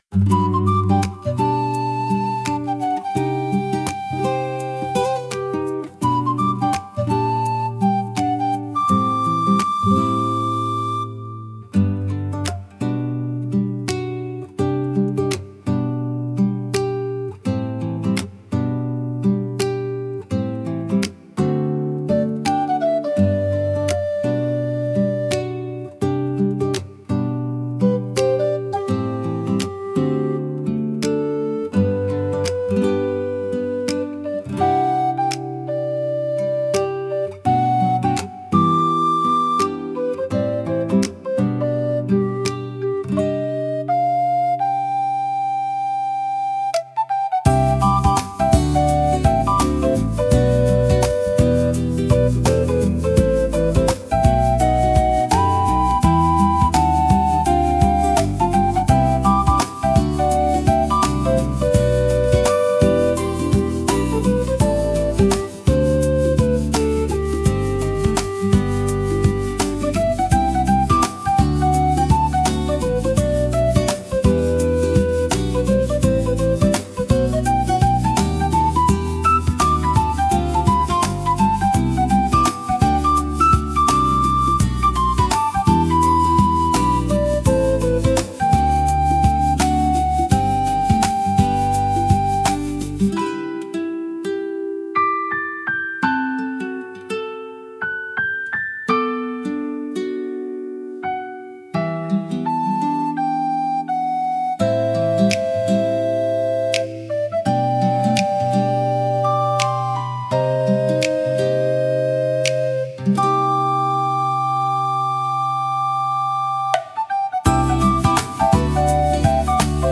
かわいい , ほのぼの , コミカル , ムービー , リコーダー , 朝 , 秋 , 穏やか